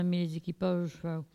Locution